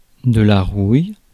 Ääntäminen
Synonyymit roux rousse corrosion carotte Ääntäminen France: IPA: [ʁuj] Haettu sana löytyi näillä lähdekielillä: ranska Käännös Substantiivit 1. ръжда {f} Muut/tuntemattomat 2. главня {f} Suku: f .